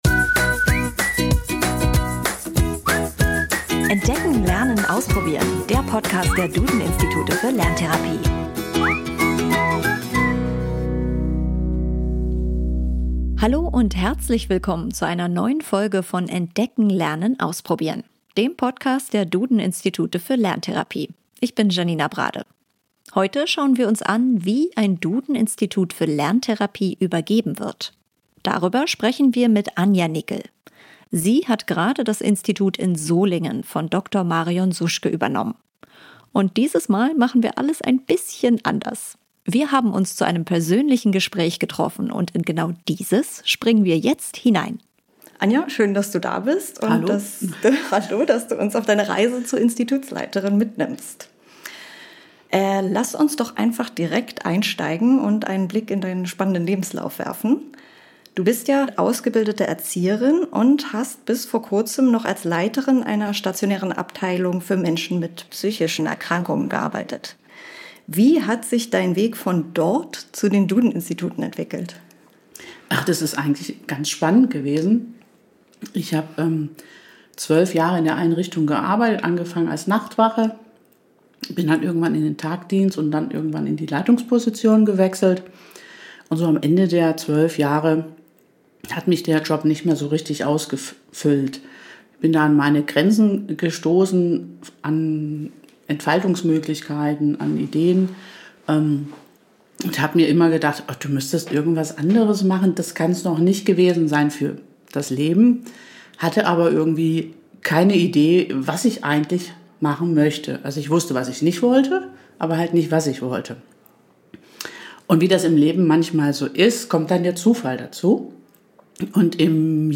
Im gemeinsamen Gespräch sprechen beide über den Übergang, die Herausforderungen und ihre Erfahrungen aus dieser besonderen Phase.